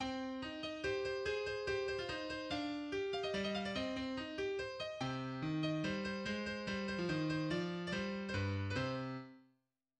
symphonie, op. 41 [G.519] - Mouvement 1 (Allegro vivo assai)